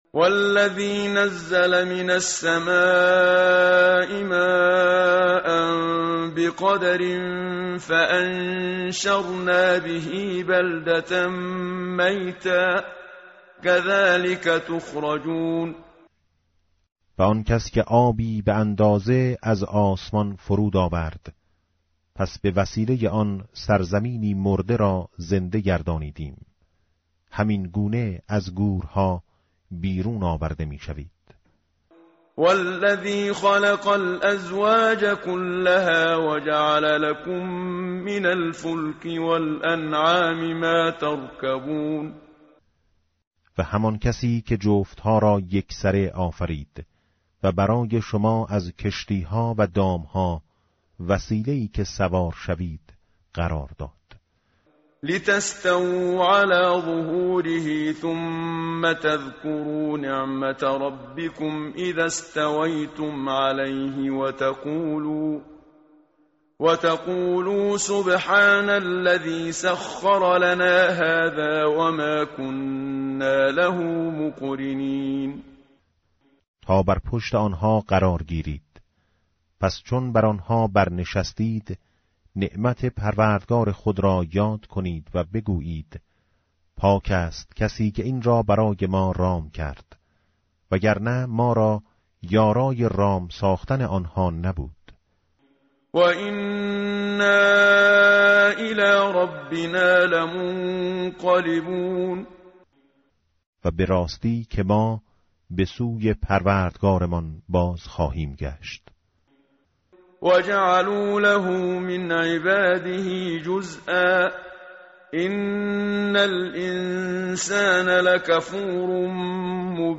tartil_menshavi va tarjome_Page_490.mp3